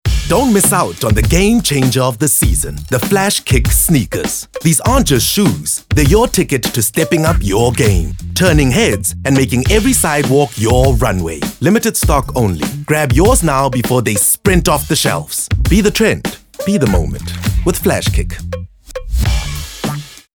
Vocal Styles:
authoritative, bright, deep, Smooth
Vocal Age:
My demo reels